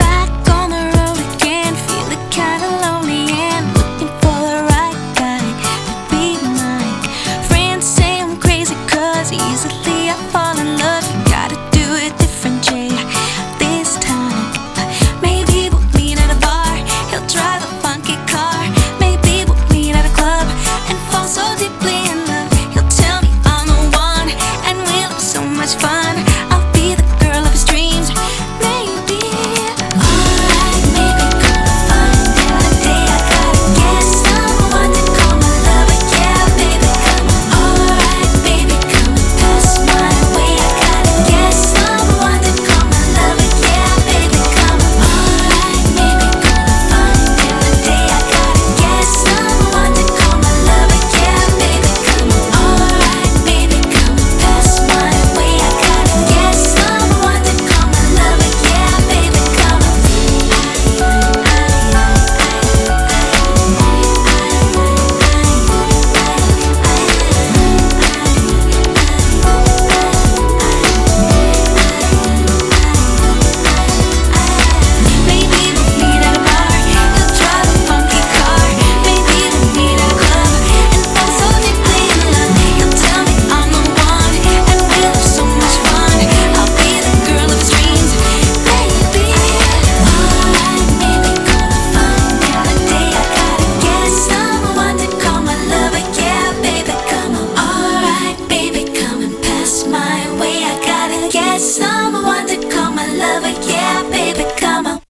BPM128
Audio QualityPerfect (High Quality)
Genre: 2-Step